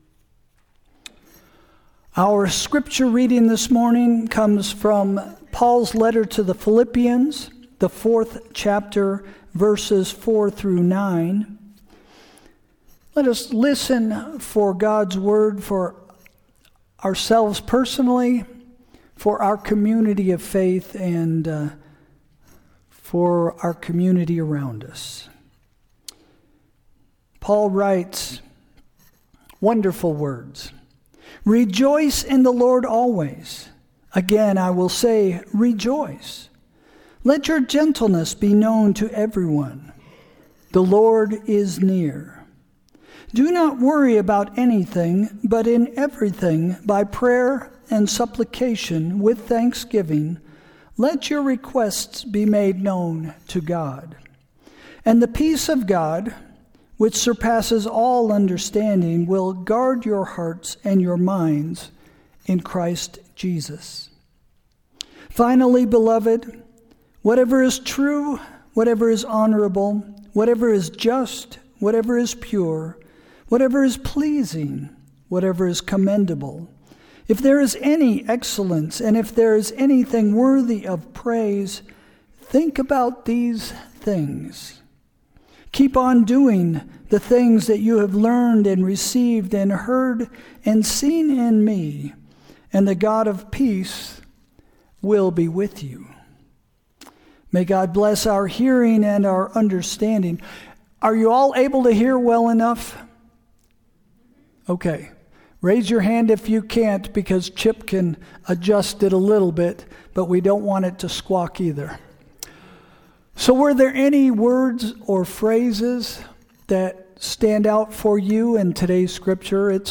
Sermon – February 15, 2026 – “10 Steps to Joy” – First Christian Church